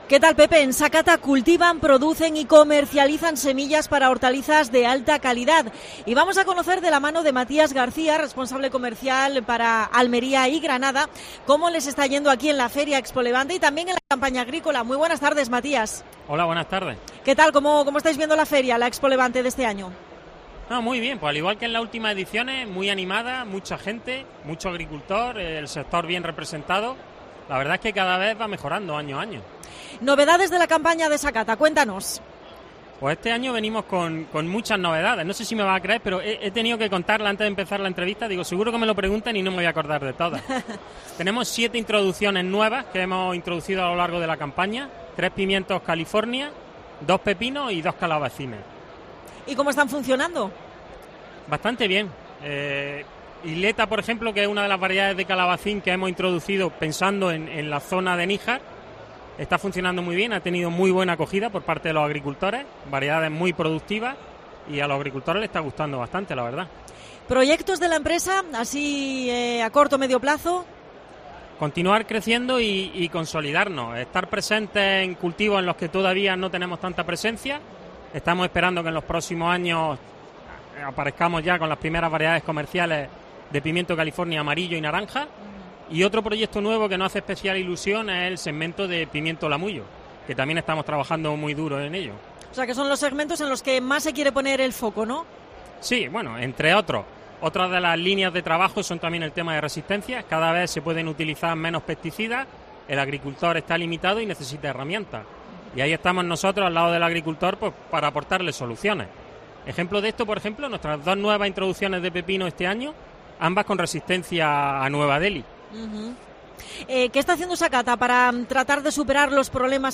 AUDIO: Especial COPE Almería desde ExpoLevante 2024.